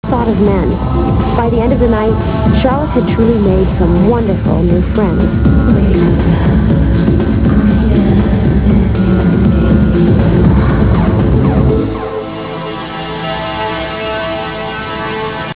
Comment: trance